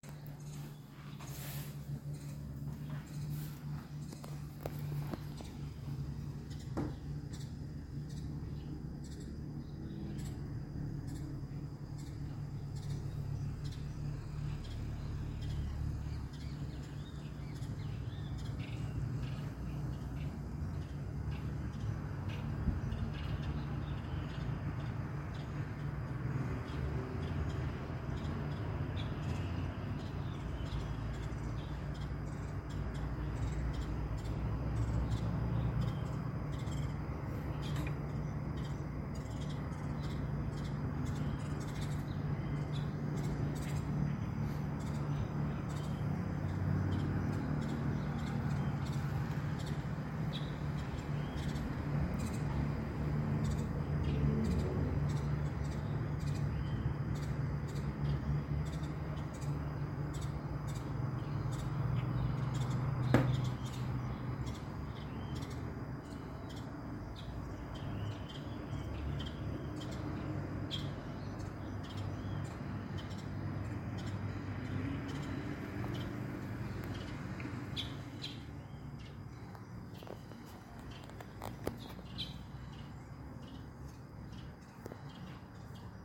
Screaming Cowbird (Molothrus rufoaxillaris)
Country: Argentina
Province / Department: Tucumán
Condition: Wild
Certainty: Recorded vocal